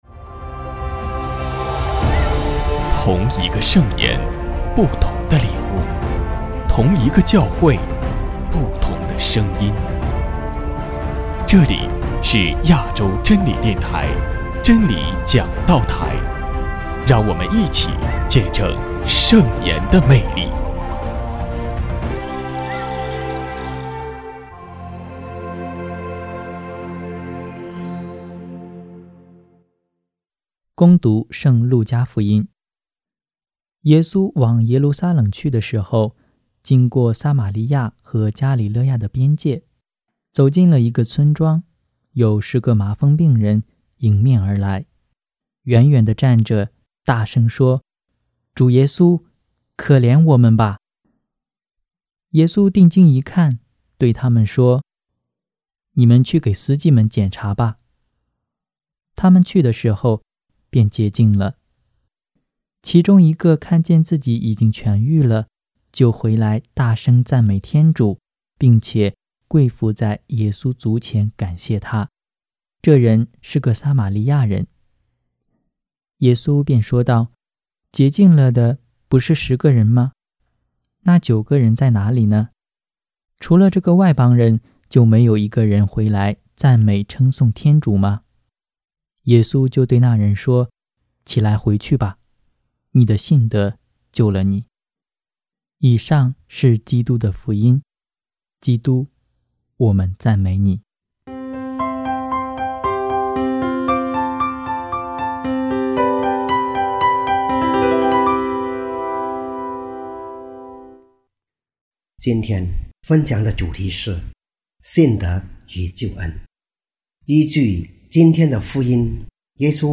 证道：